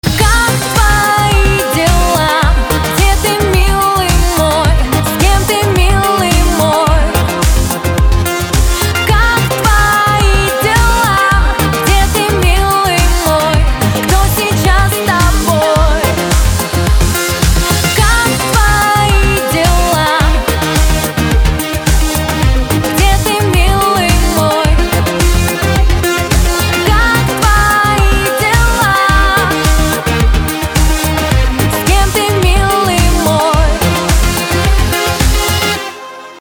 • Качество: 256, Stereo
женский вокал
dance